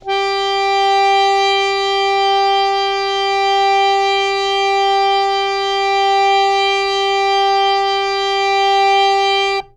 harmonium
G4.wav